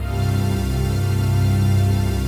DM PAD2-72.wav